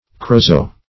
Corozo \Co*ro"zo\ Corosso \Co*ros"so\ (k?-r?"th? or -s?), n.